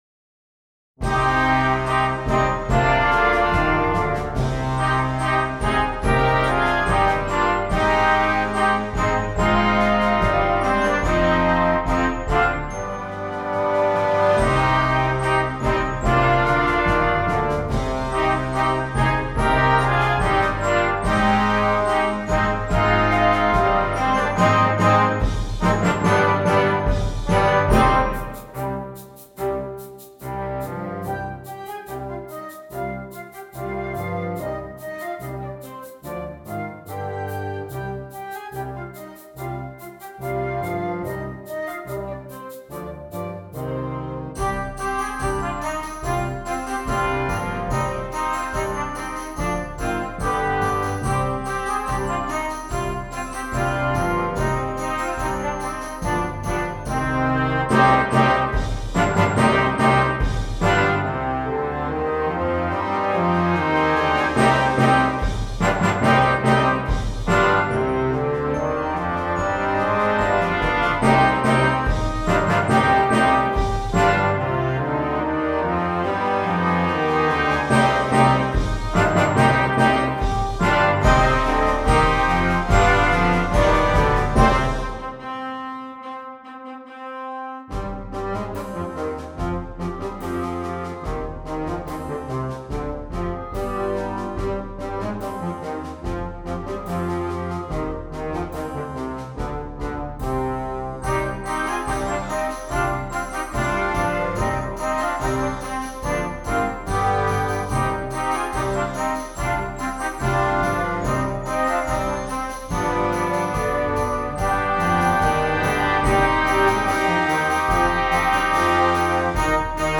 Concert Band